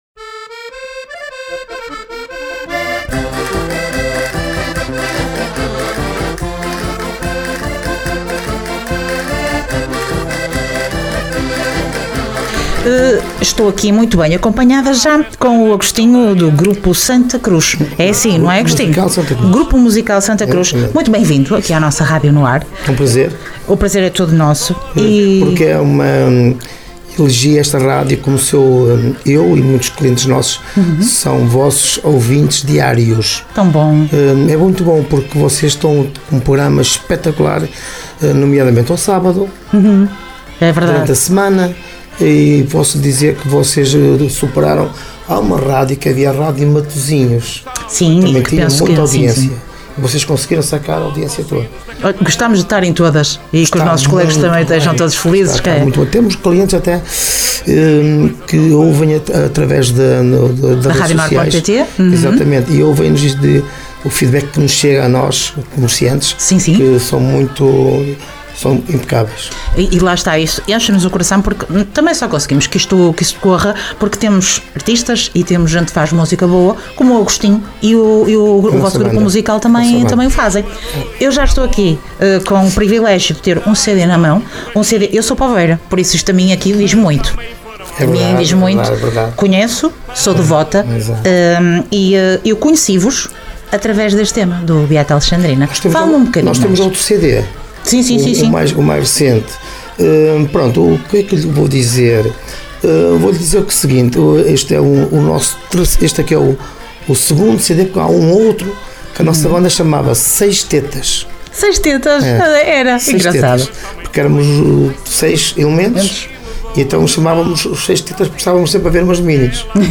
Entrevista Grupo Musical Santacruz